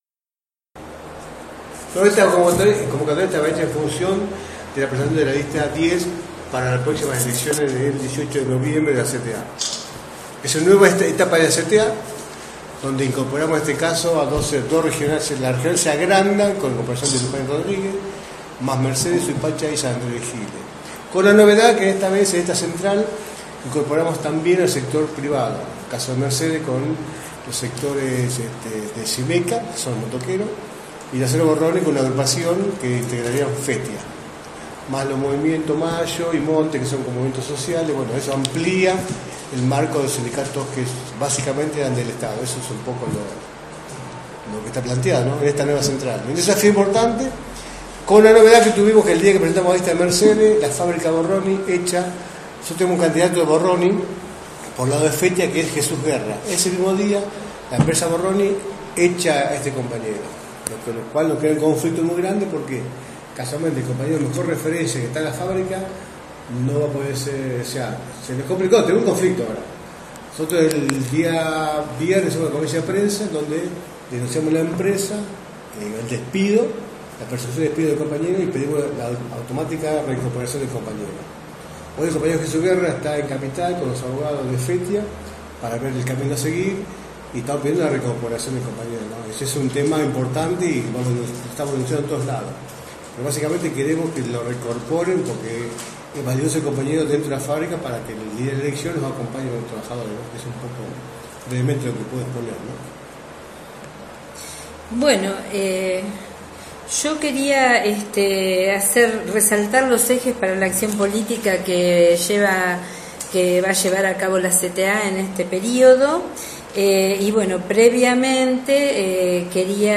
El Lunes 27 de Octubre se llev� a cabo en la sede de SUTEBA Lujan, la Presentaci�n oficial de la Lista 10, para las pr�ximas elecciones de la CTA de los trabajadores del dia 18 de Noviembre.
conferencia_cta_lista_10_-_audio.mp3